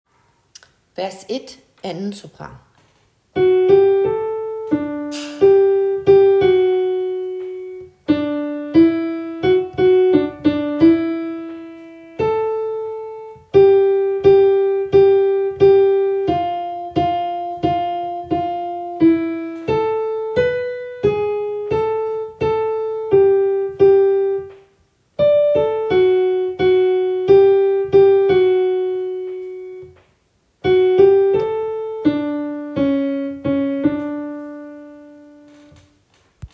Kun koret